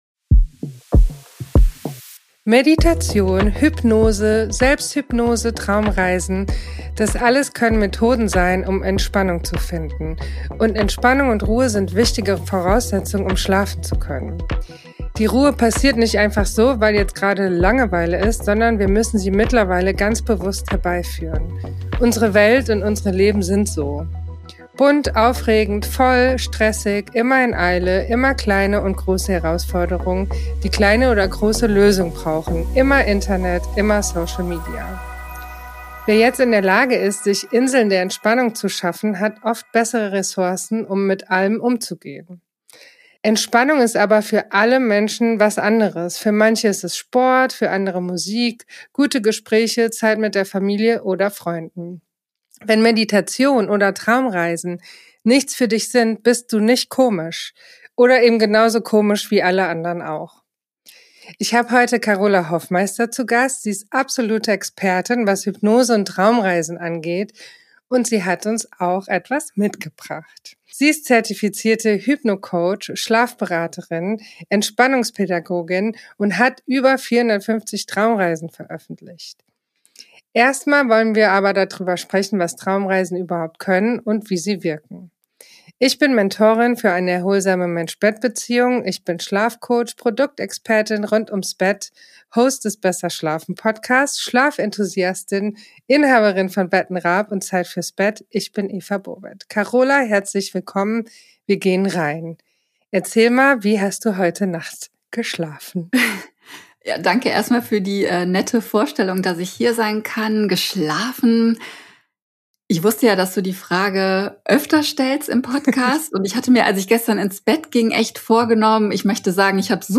Tiefe Entspannung, innere Bilder & ein sanfter, ruhiger Ausklang